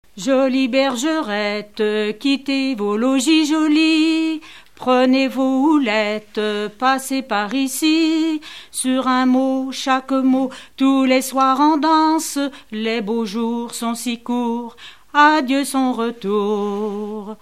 Rondes à baisers et à mariages fictifs
Pièce musicale inédite